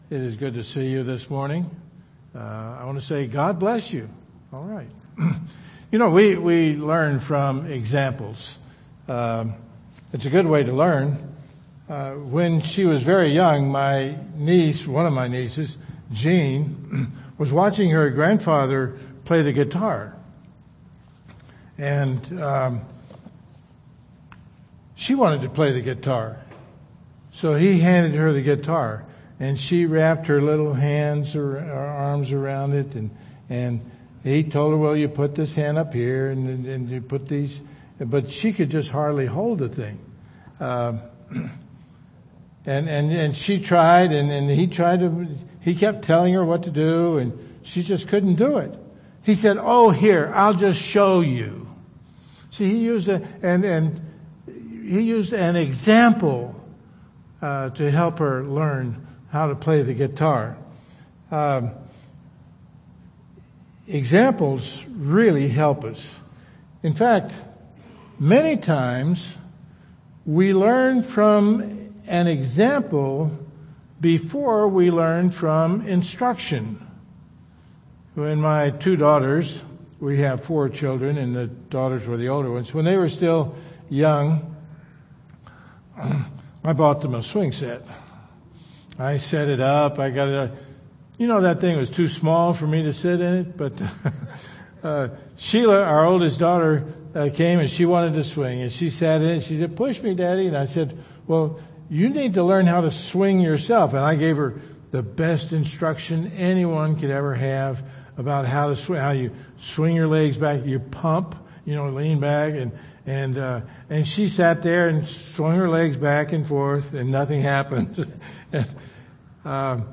September 12, 2021 Worship Service
Service Type: Live Service